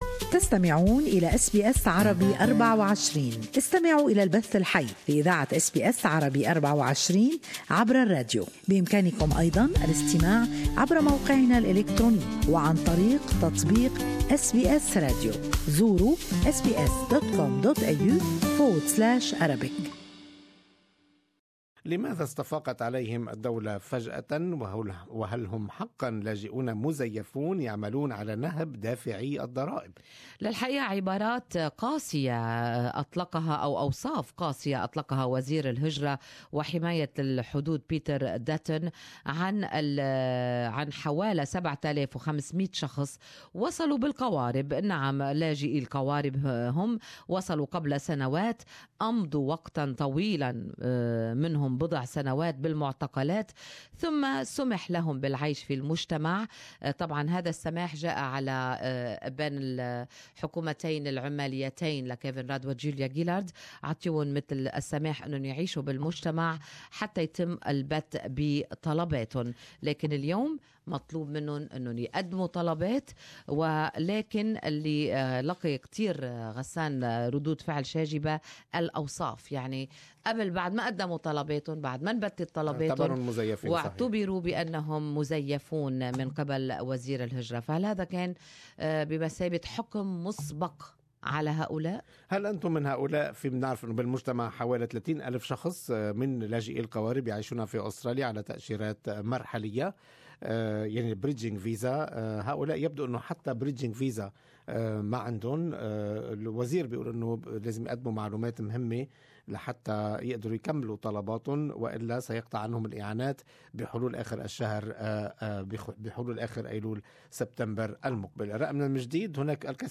Good Morning Australia listeners share their opinions.